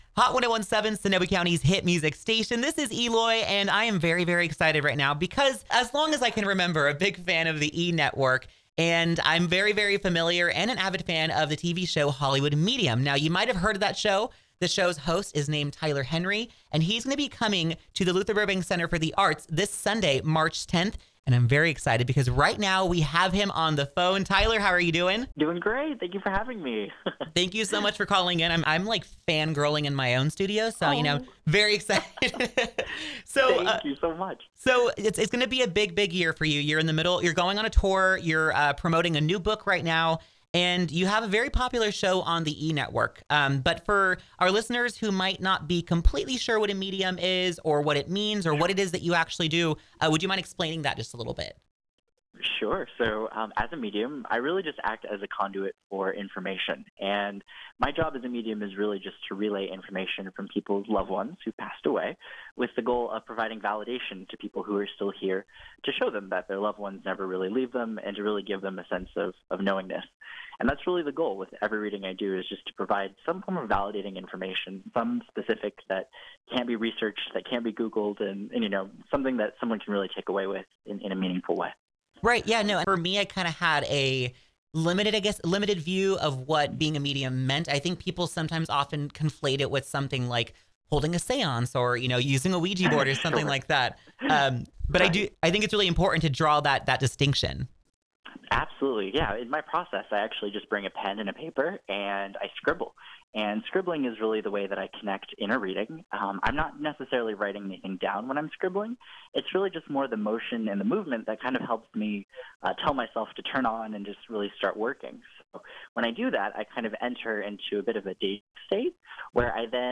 Tyler-Henry-INterview.wav